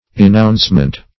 Enouncement \E*nounce"ment\, n. Act of enouncing; that which is enounced.